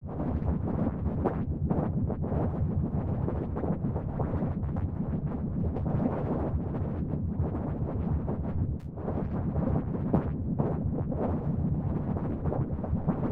decay-in-water.mp3